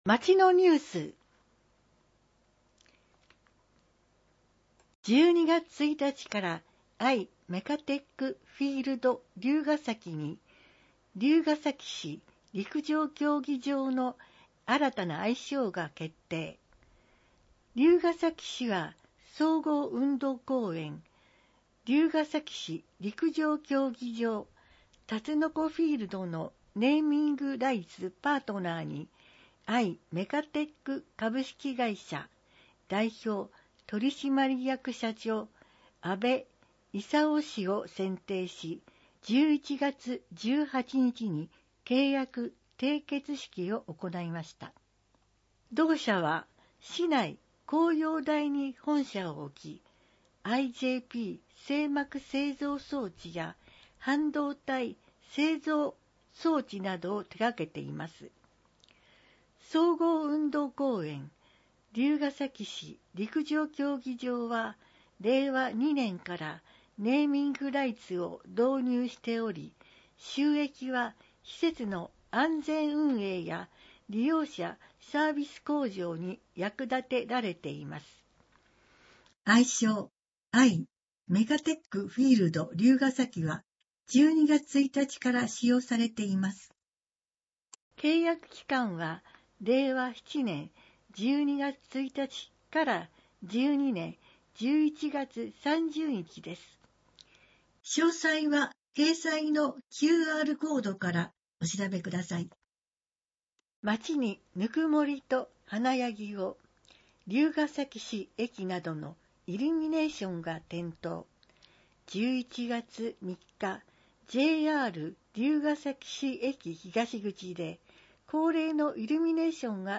『りゅうほー』の音訳CD・点訳版を配布しています